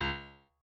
piano9_27.ogg